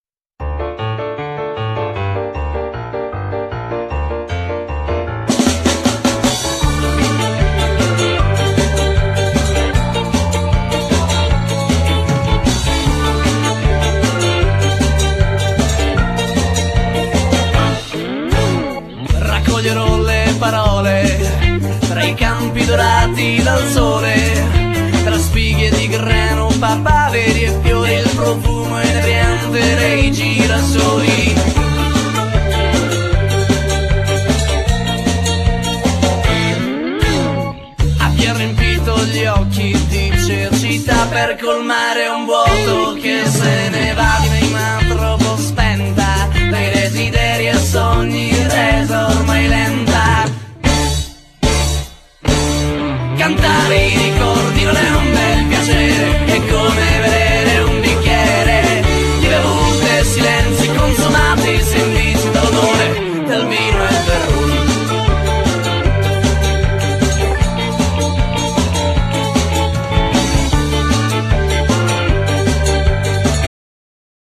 Genere : Pop
La band è composta da 6 elementi :
voce
batteria
synth e cori
basso
chitarra
Piano